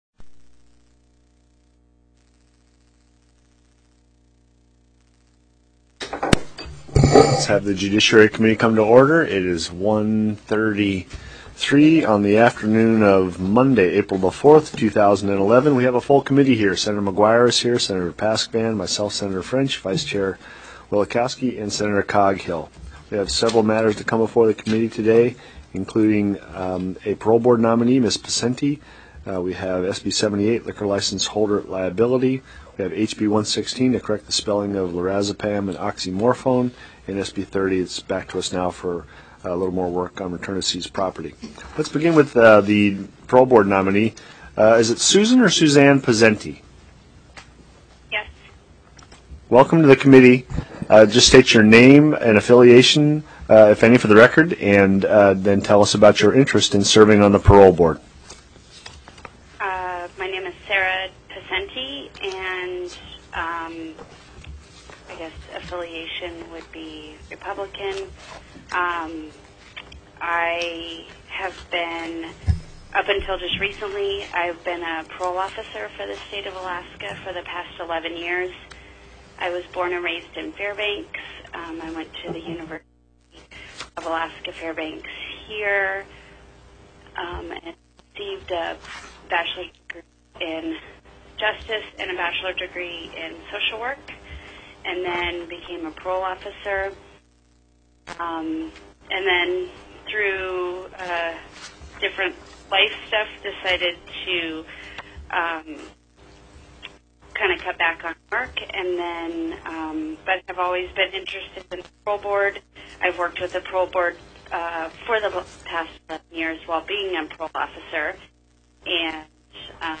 TELECONFERENCED
CHAIR FRENCH announced the consideration of SB 30 and asked for a motion to adopt the proposed committee substitute (CS).